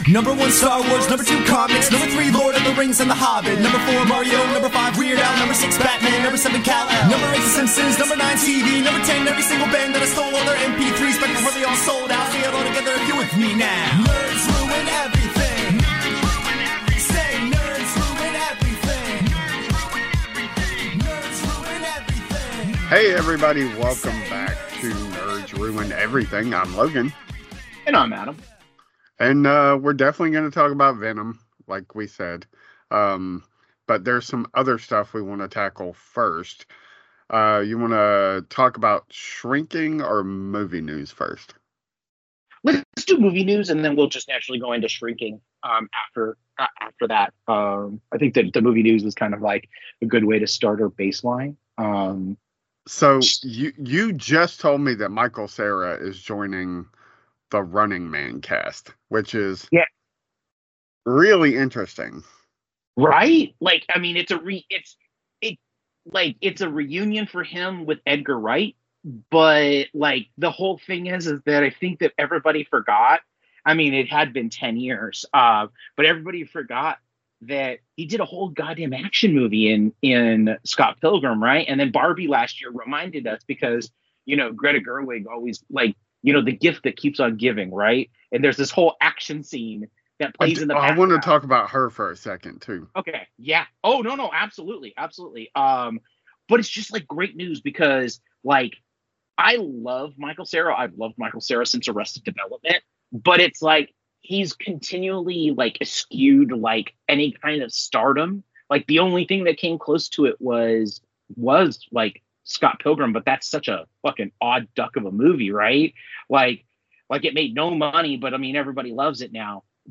two nerds